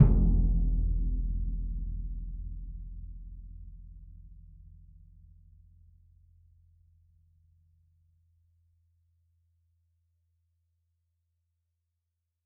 bassdrum_hit_ff.wav